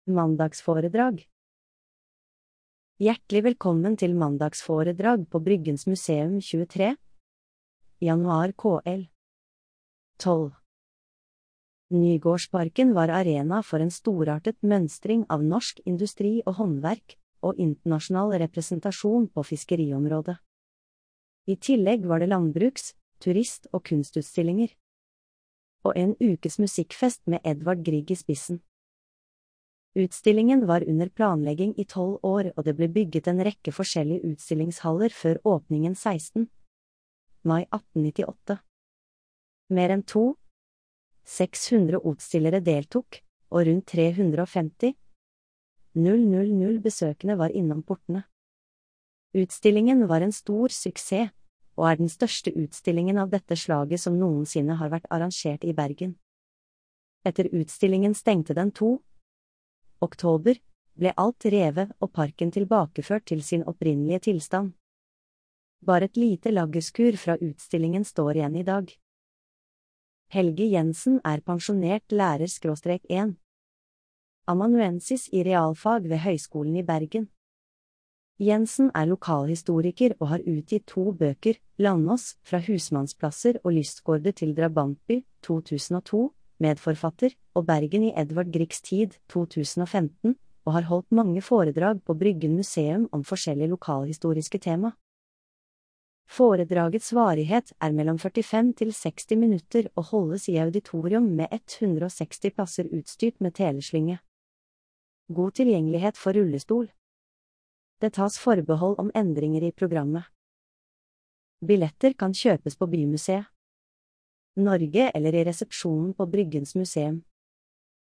Mandagsforedrag - Bymuseet
Populærvitenskapelige foredrag med et vidt spent av tema i auditoriet på Bryggens Museum.